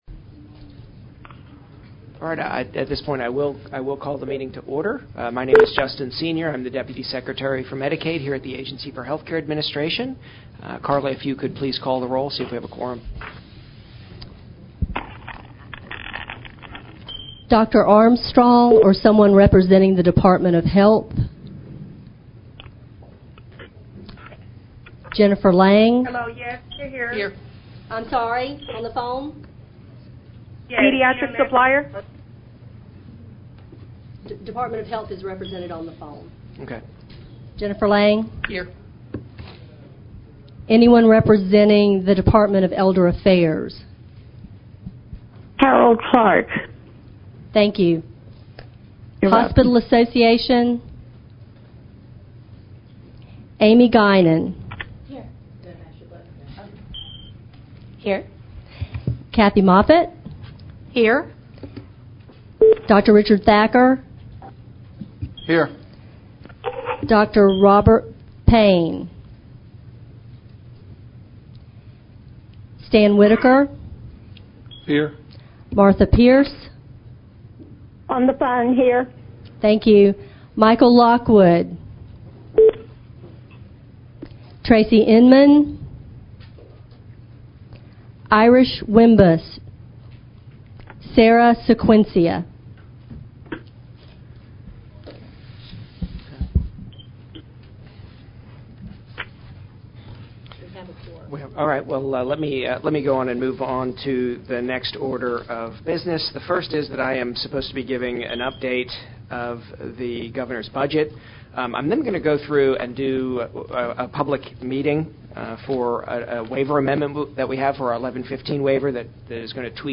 Audio of Meeting